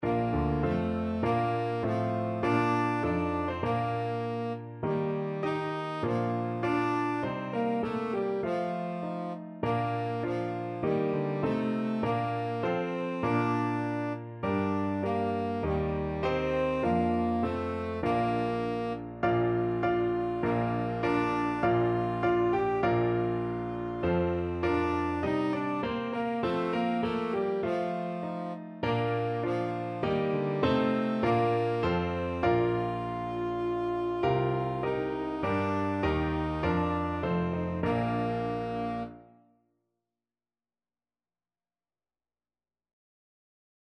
Free Sheet music for Tenor Saxophone
Bb major (Sounding Pitch) C major (Tenor Saxophone in Bb) (View more Bb major Music for Tenor Saxophone )
Andante
4/4 (View more 4/4 Music)
Traditional (View more Traditional Tenor Saxophone Music)